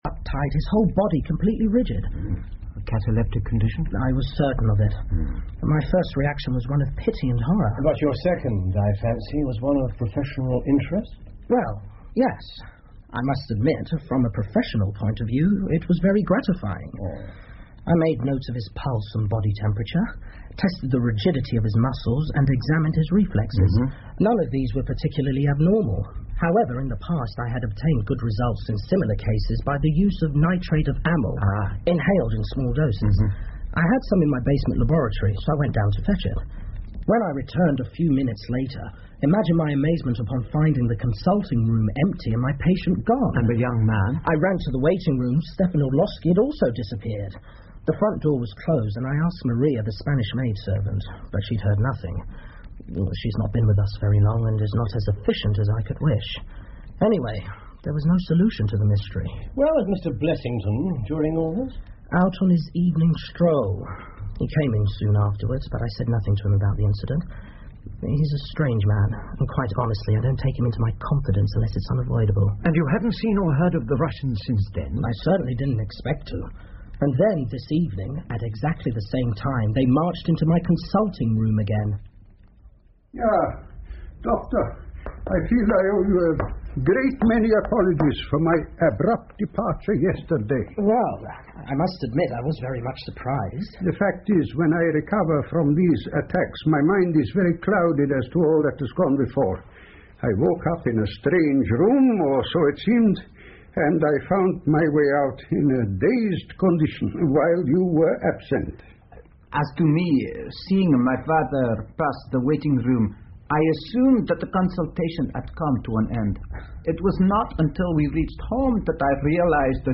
福尔摩斯广播剧 The Resident Patient 4 听力文件下载—在线英语听力室